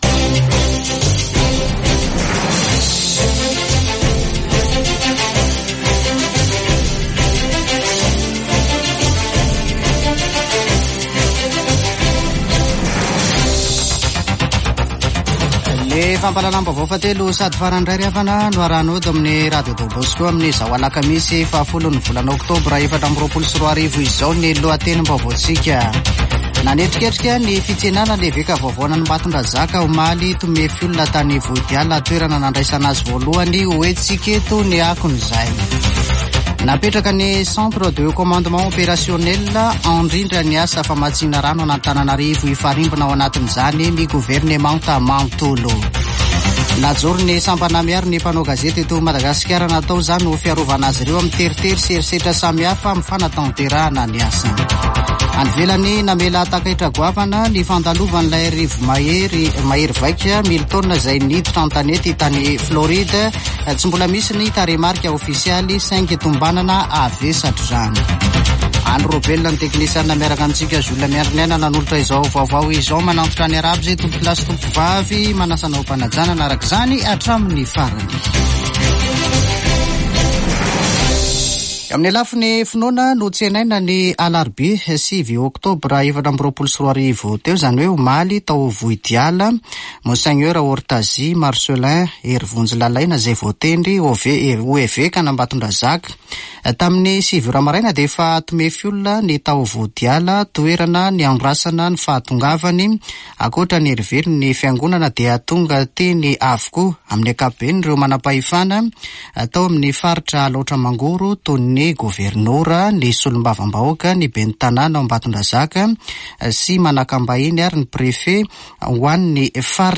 [Vaovao hariva] Alakamisy 10 ôktôbra 2024